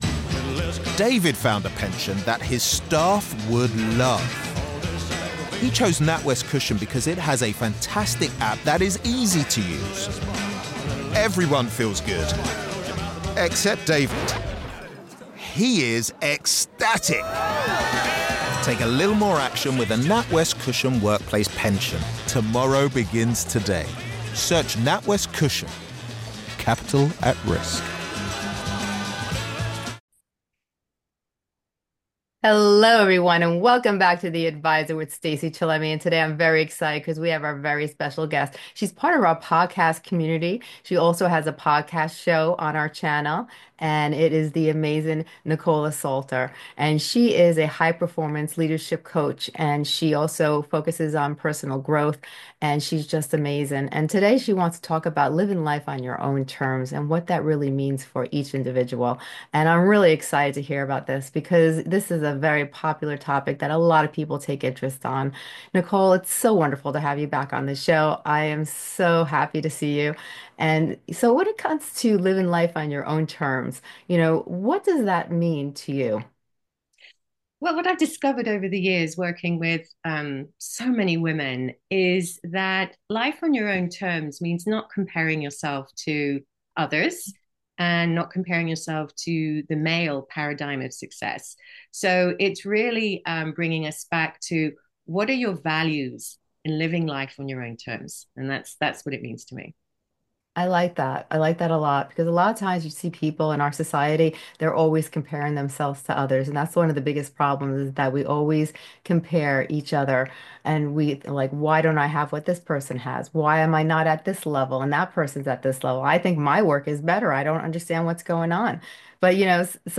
From the freedom to speak your mind to the freedom to chase your dreams, we'll delve into the complexities and nuances of this fundamental human right. Join us as we spark a thought-provoking conversation about the meaning of freedom and how it impacts our lives.